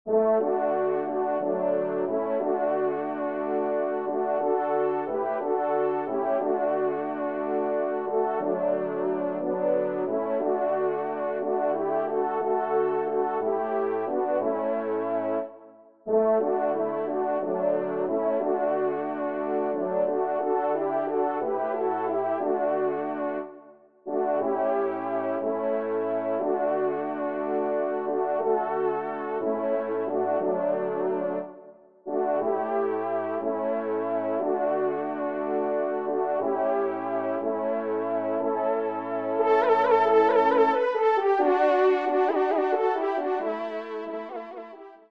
TON DE VÈNERIE   :
ENSEMBLE